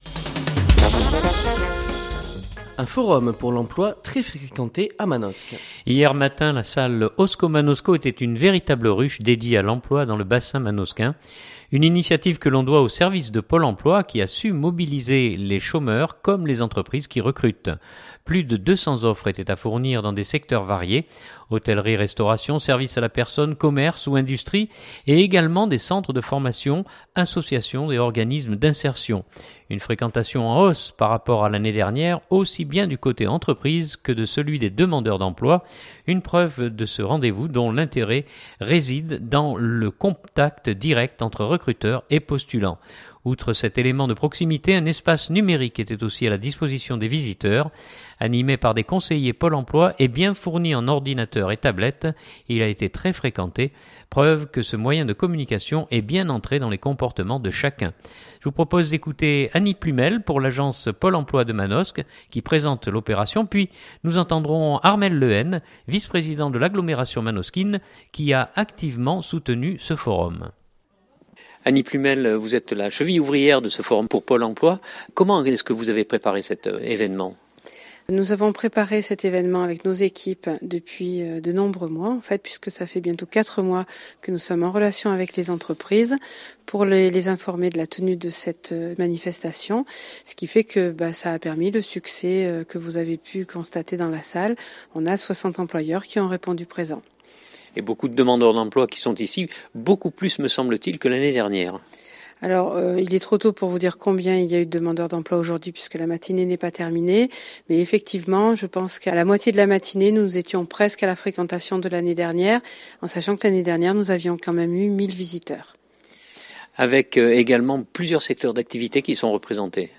Puis nous entendrons Armel Le Hen, vice-président de l’agglomération manosquine qui a activement soutenu ce forum. écouter ou Télécharger 2016-10-21 - Manosque - Forum emploi.mp3 (3.21 Mo) Durée : 4' 45''